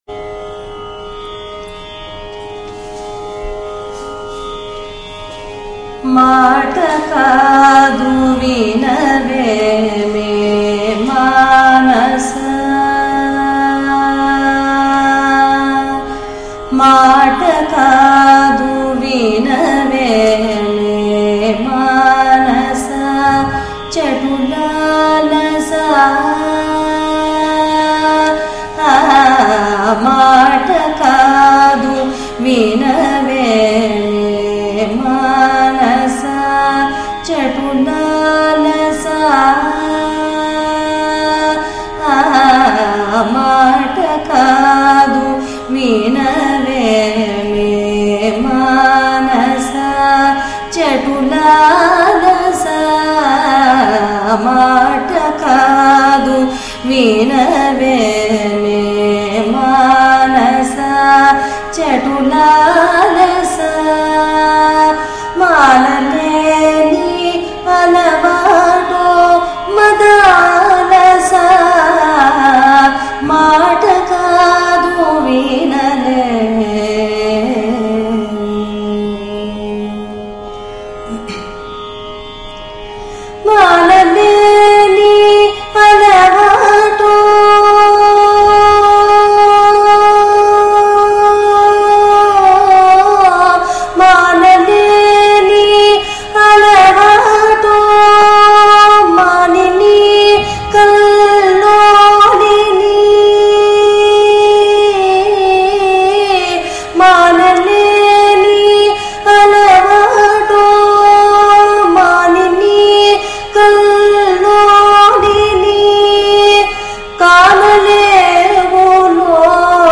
రాగం: చక్రవాకం తాళం: రూపక